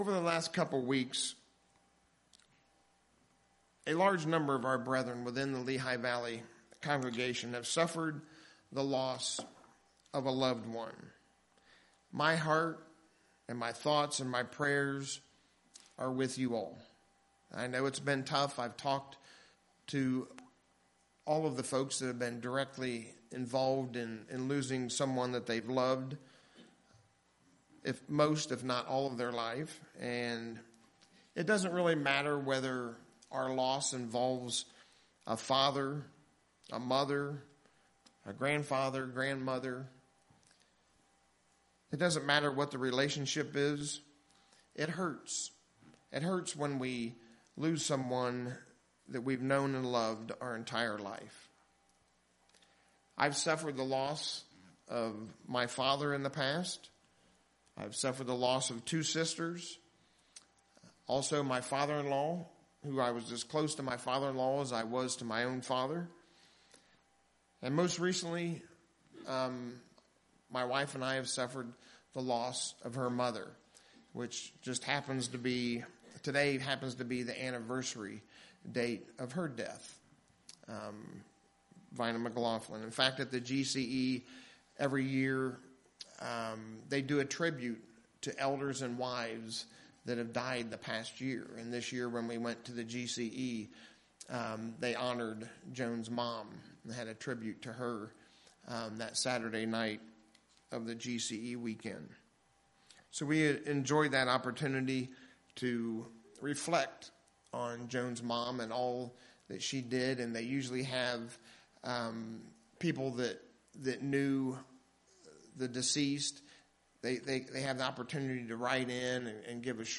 In Matthew 5:4, Jesus tells us we're blessed if we're in a state of mourning and He tells us we'll be comforted. In our sermon today we'll learn more about the importance of mourning, and the hope that God has given us through His plan of salvation.
Given in Lehigh Valley, PA